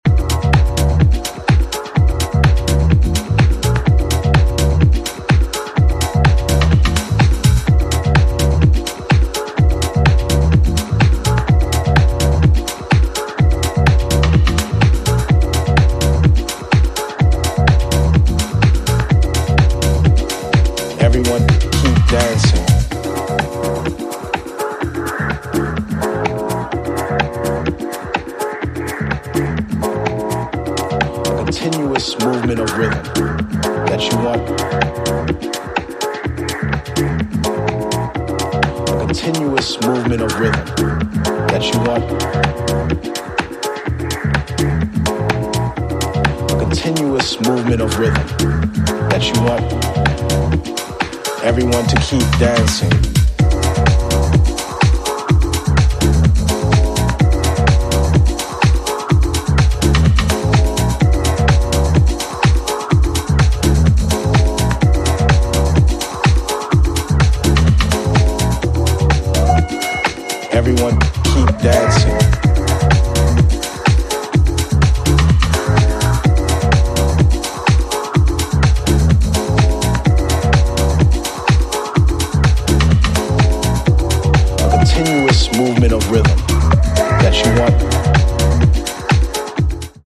> HOUSE・TECHNO
サンプリングを主体にウォーミーでファットなディスコ・ハウスを展開！
ジャンル(スタイル) DEEP HOUSE / DISCO HOUSE